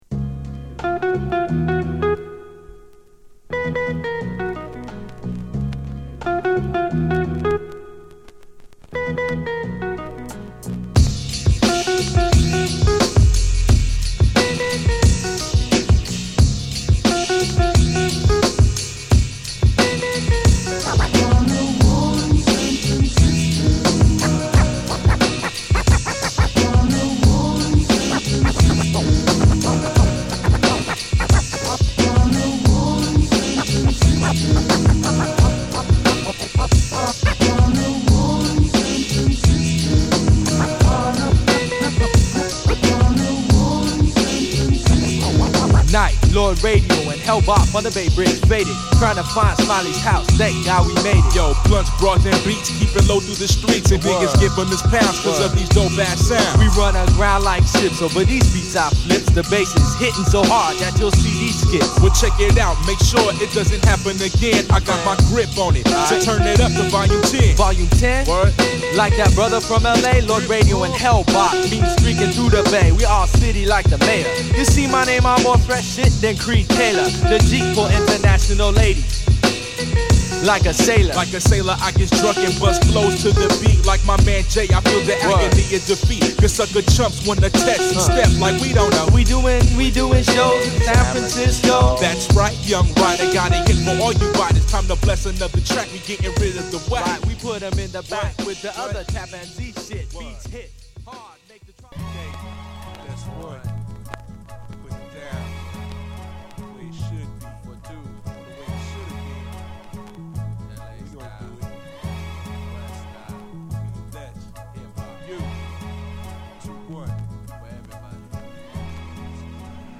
スムースなJazzギターを華麗にループした